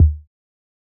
Kick (10).wav